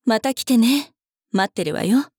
大人女性│女魔導師│店番ボイス